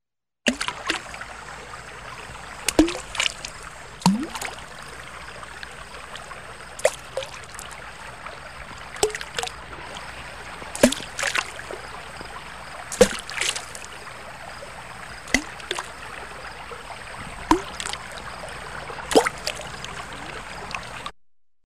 На этой странице собраны звуки камнепада — от легкого шелеста скатывающихся камешков до грохота крупных обвалов.
10. Звуки камня, падающего в воду